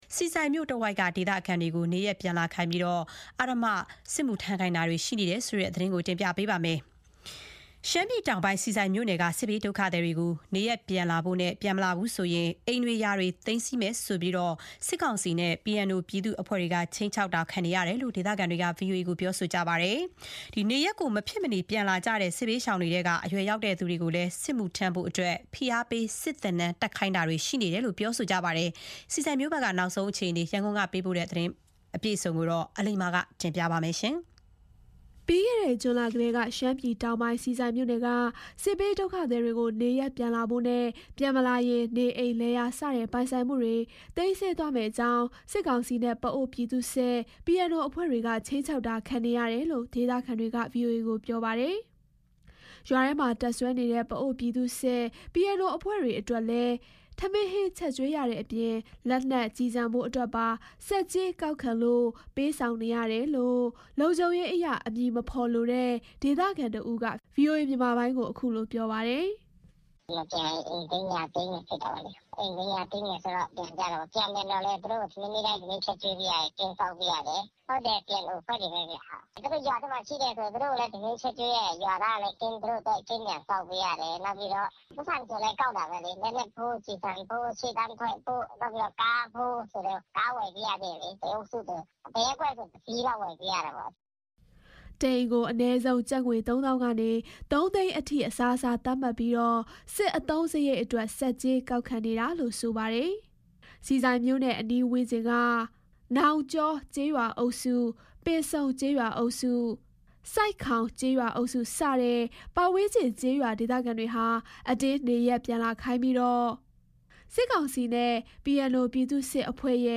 စစ်သင်တန်းပြီးဆုံးလို့ ကိုယ့်ရပ်ရွာမှာ လုံခြုံရေးတာဝန်ထမ်းဆောင်ရသူတွေ ရှိသလို ရှေ့တန်း ပို့ခံရသူတွေလည်း ရှိနေတယ်လို့ လုံခြုံရေးအရ အမည်မဖော်လိုတဲ့ ဒေသခံတဦးက ဗွီအိုအေကို ခုလို ပြောပါတယ်။